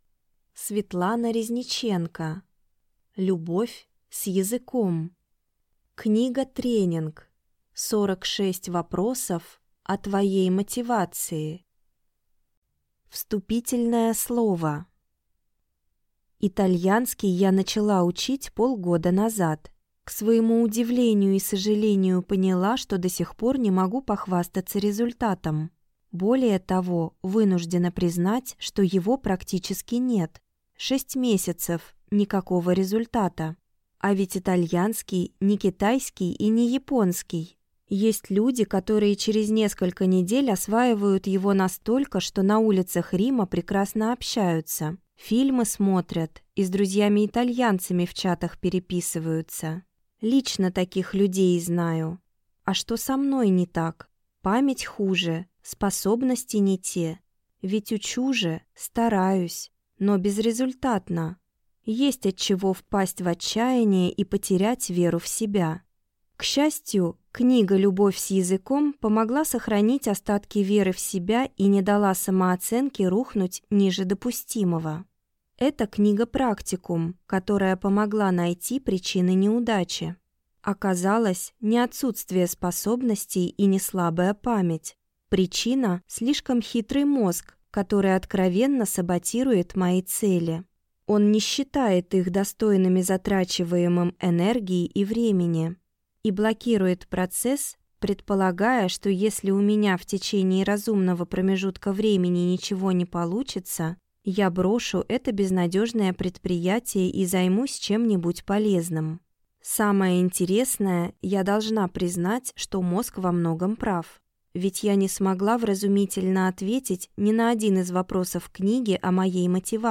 Аудиокнига Любовь с языком | Библиотека аудиокниг